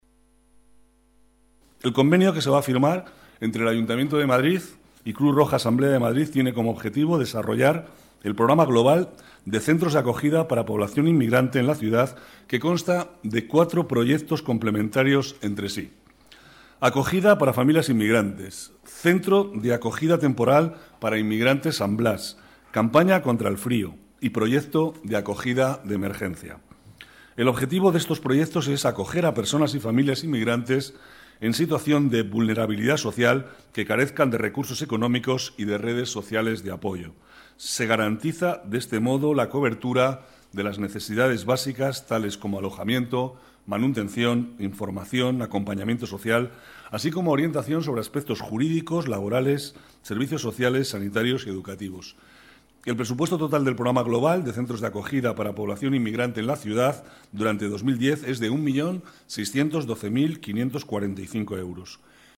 Nueva ventana:Declaraciones del vicealcalde de Madrid, Manuel Cobo